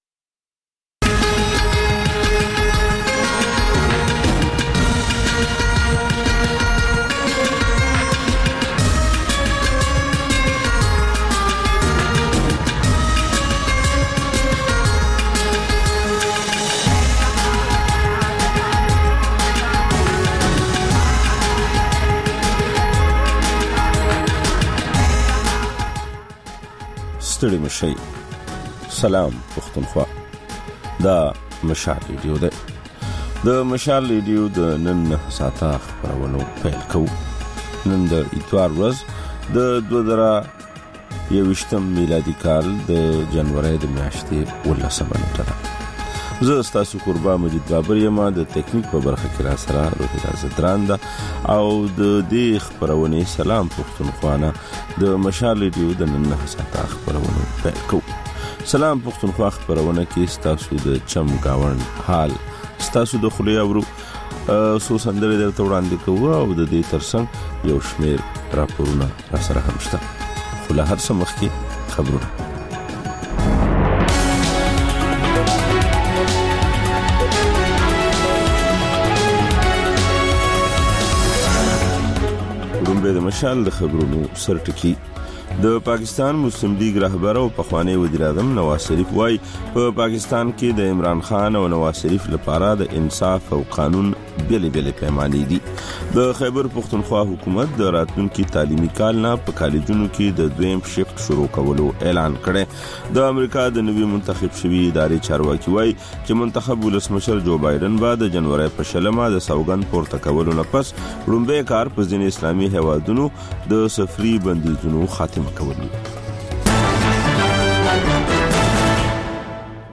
دا د مشال راډیو لومړۍ خپرونه ده چې په کې تر خبرونو وروسته رپورټونه، له خبریالانو خبرونه او رپورټونه او سندرې در خپروو.